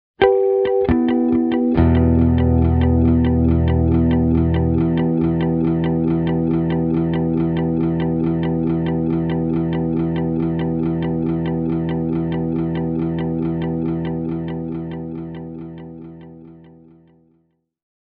Tältä Flashback X4:n efektityypit kuulostavat: